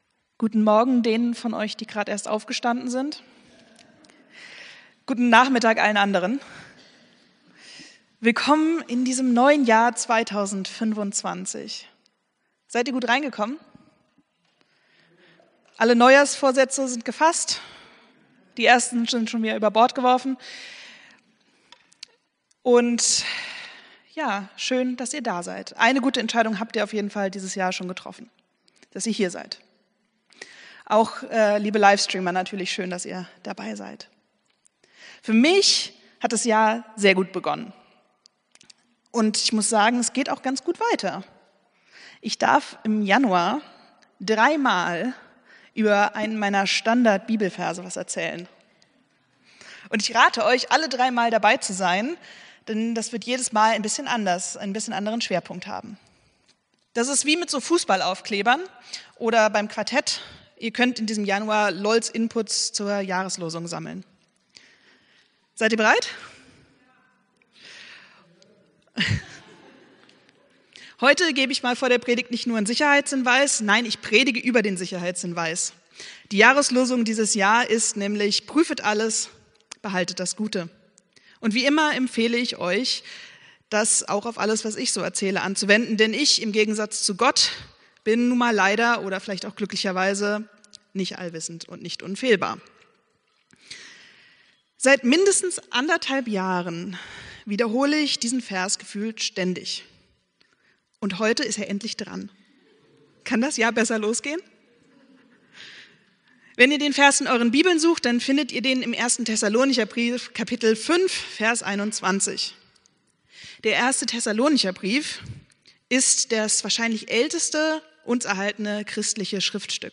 Predigt vom 01.01.2025